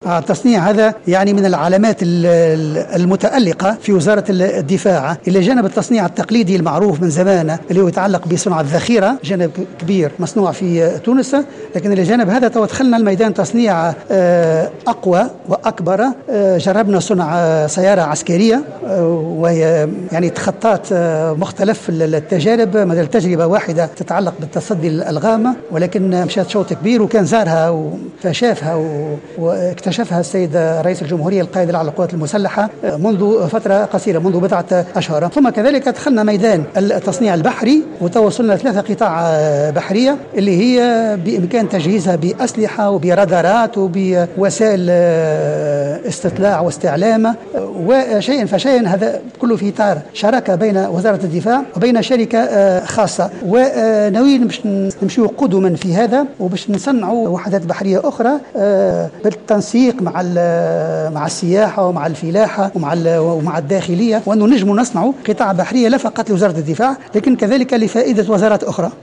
أكدّ وزير الدفاع الوطني إبراهيم البرتاجي خلال الاستماع إليه صباح اليوم الخميس صلب لجنة تنظيم الإدارة و شُؤون القوات الحاملة للسلاح أنّ القوّات العسكرية التونسية نجحت في صناعة سيارة عسكرية وقد تخطت مختلف التجارب وكان قد زارها رئيس الجمهورية منذ بضعة أشهر حسب قوله.